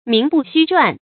注音：ㄇㄧㄥˊ ㄅㄨˋ ㄒㄩ ㄔㄨㄢˊ
名不虛傳的讀法